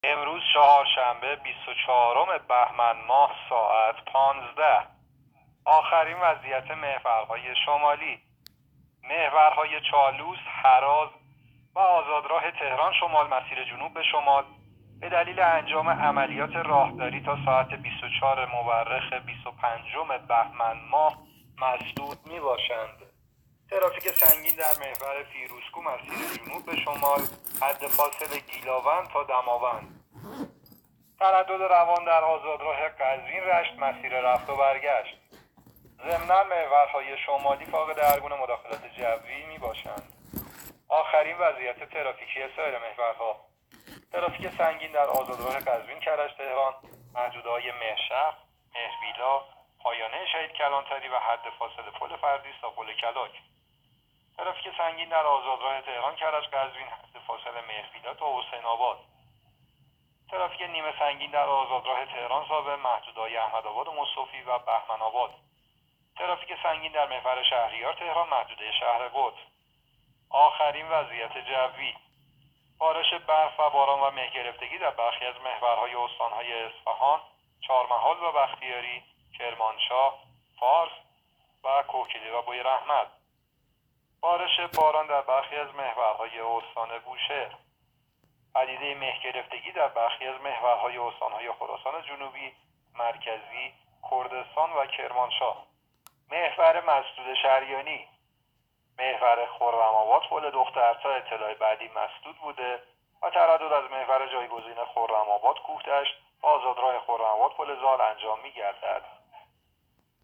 گزارش رادیو اینترنتی از آخرین وضعیت ترافیکی جاده‌ها ساعت ۱۵ بیست و چهارم بهمن؛